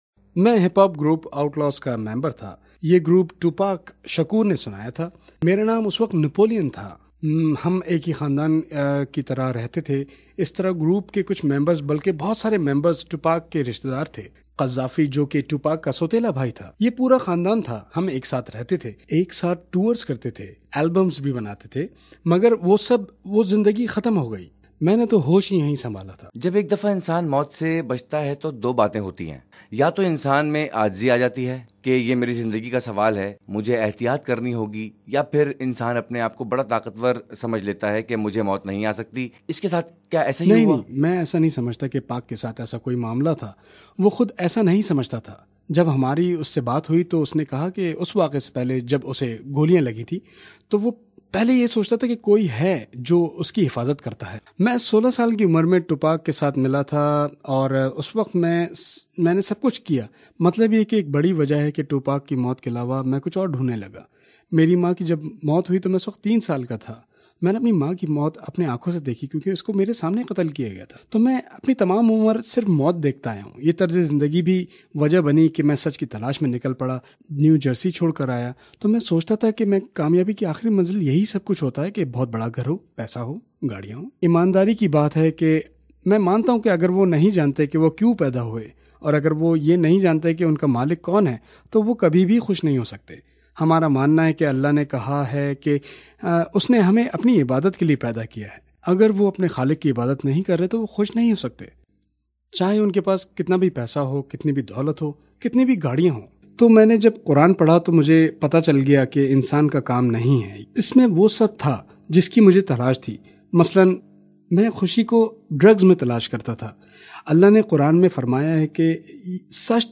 Napoleon Interview in Urdu